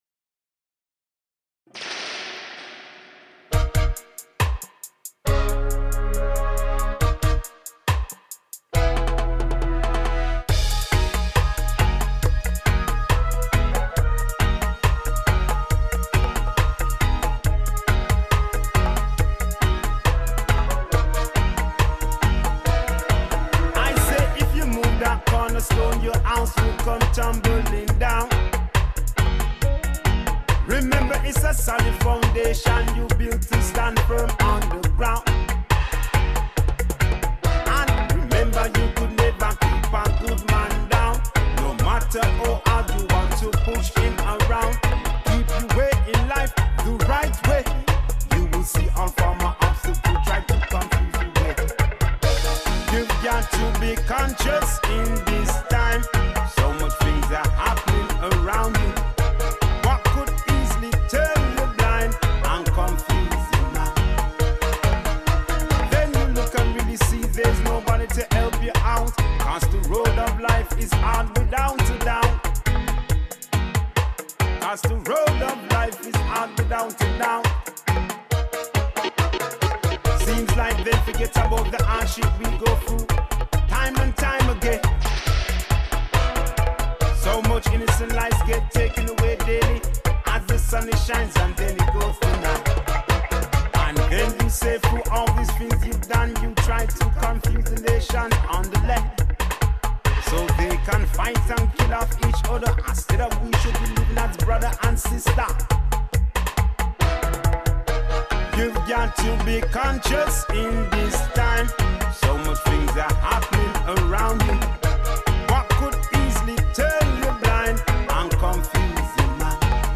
DRUM RECORDED AT HALL PLCE STUDIO LDS UK